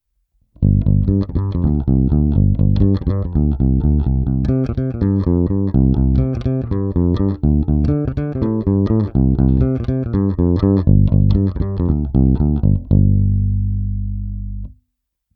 V tomto případě jsem dostal obě basy vybavené ocelovými hlazenými strunami.
Nahrávky jsou provedeny rovnou do zvukovky a dále kromě normalizace ponechány bez úprav.